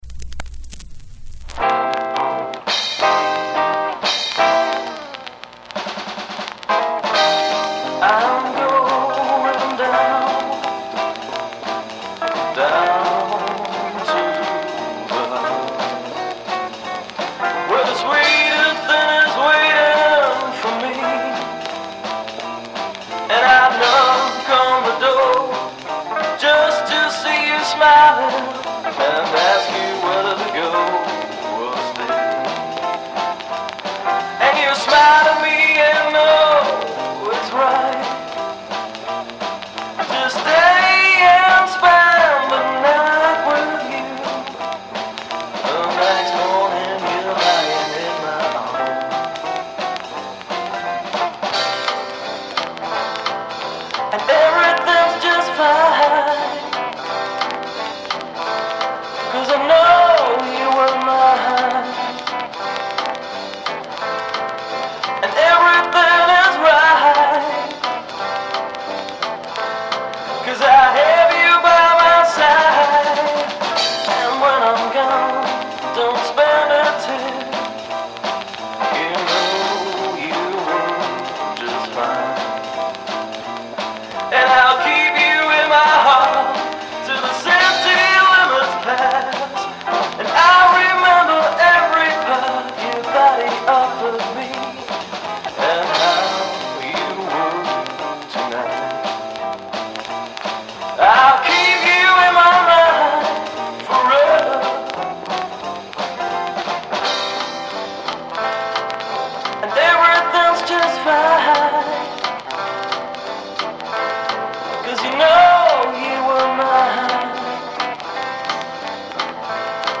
aussenjam72_jjvinyl.mp3